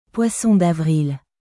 Poisson d’avrilポワソン ダヴリル